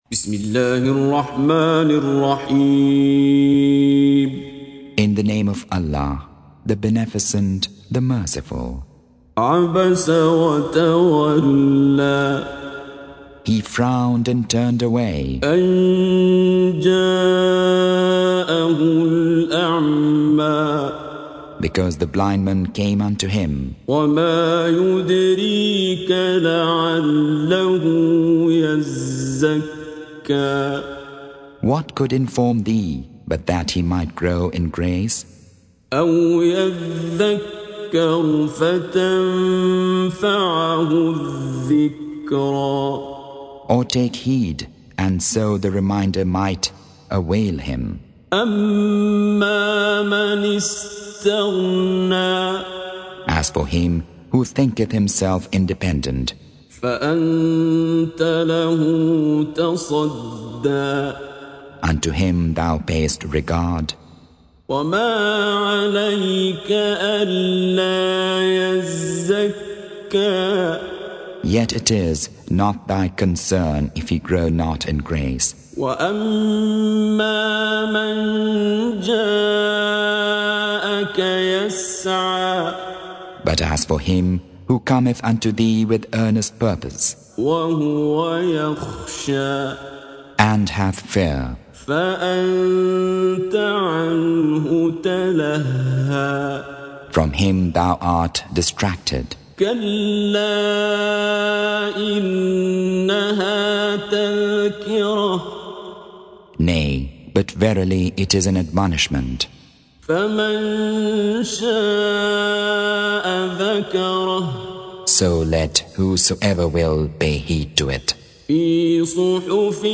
Surah Sequence تتابع السورة Download Surah حمّل السورة Reciting Mutarjamah Translation Audio for 80.